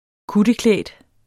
Udtale [ ˈkudəˌklεˀd ]